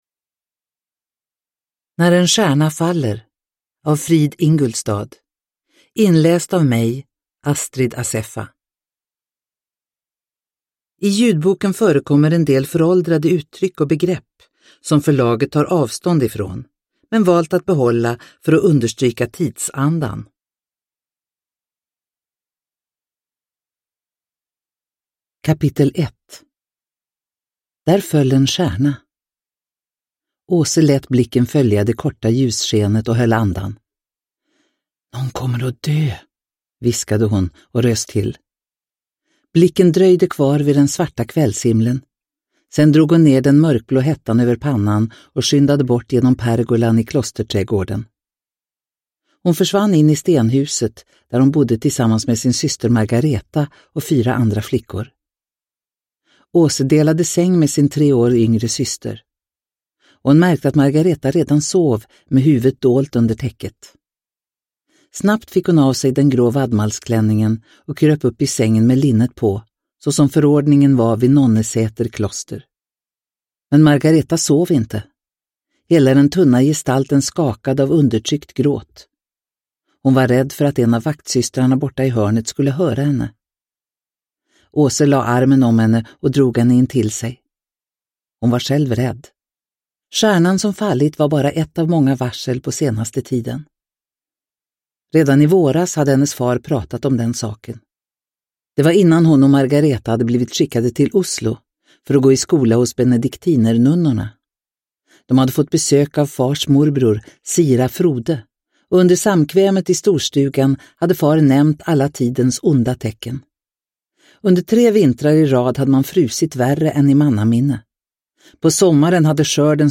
När en stjärna faller – Ljudbok – Laddas ner